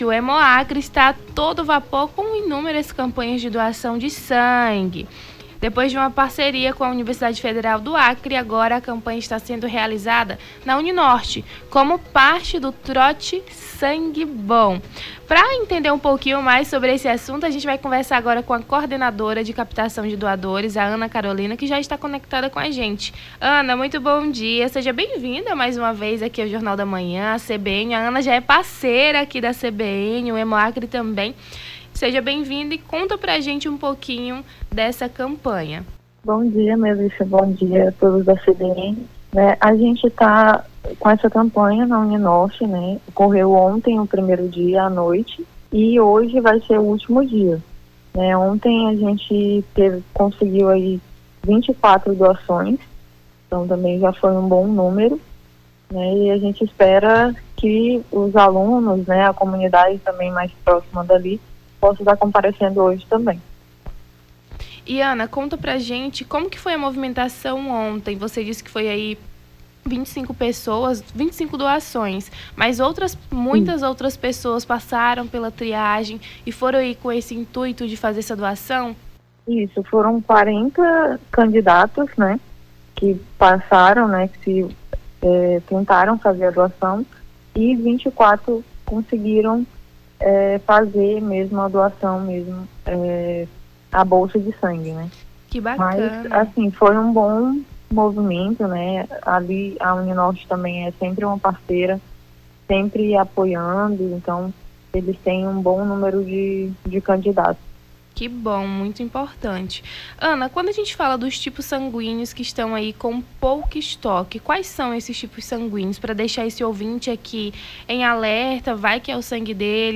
Nome do Artista - CENSURA - ENTREVISTA HEMOACRE NA UNINORTE (28-08-24).mp3